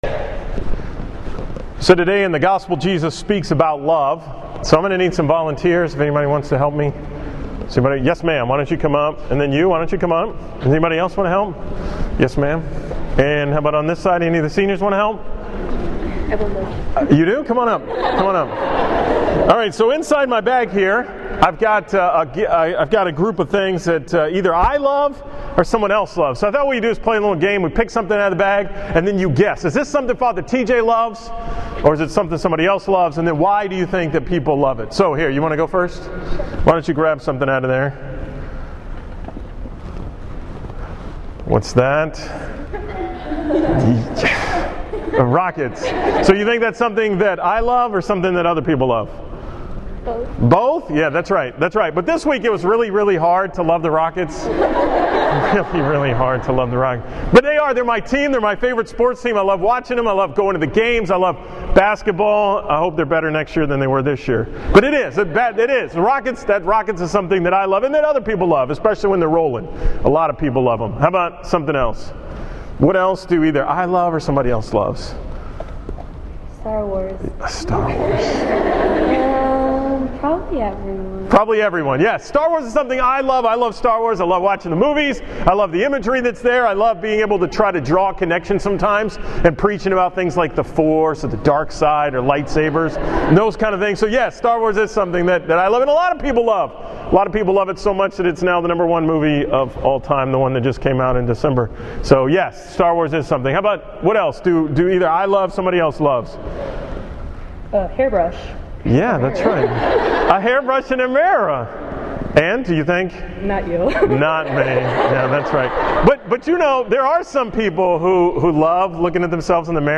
From the Mass at IWA on April 29, 2016